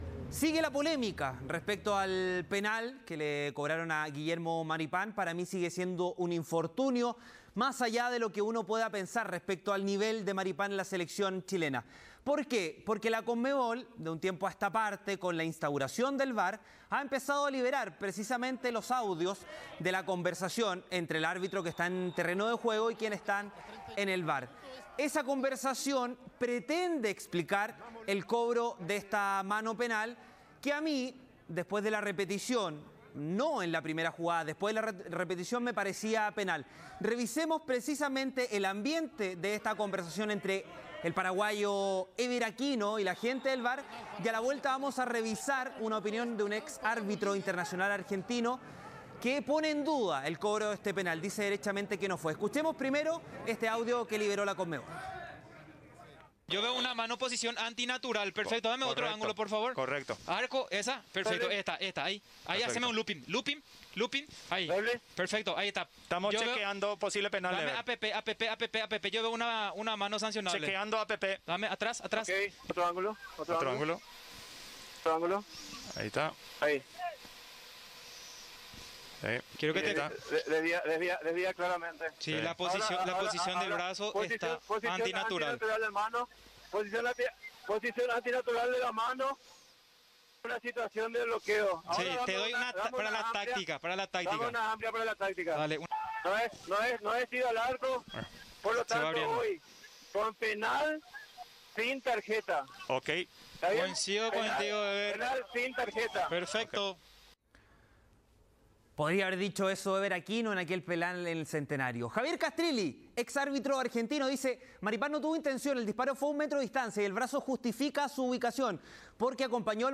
AUDIO-VAR-PARTIDO-CHILE-BOLIVIA.mp3